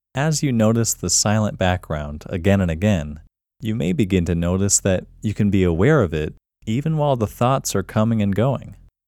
QUIETNESS Male English 11
The-Quietness-Technique-Male-English-11.mp3